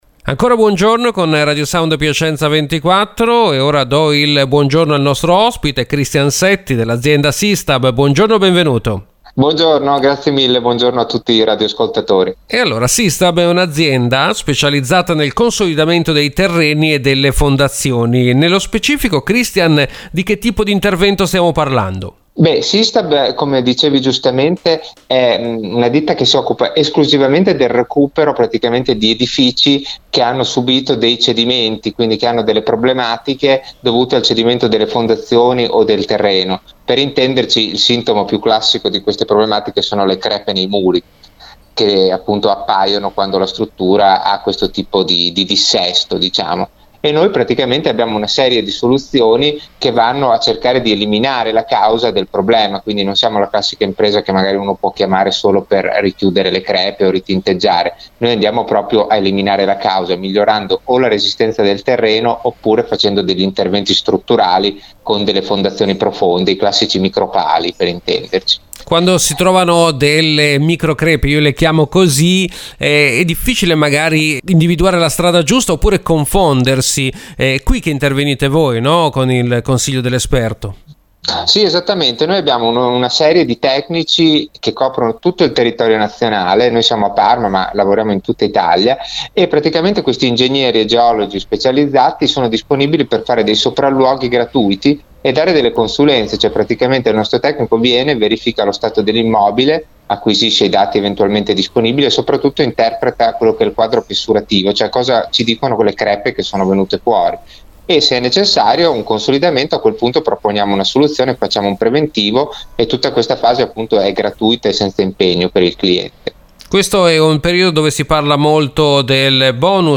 intervista-radio-sound-systab.mp3